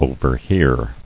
[o·ver·hear]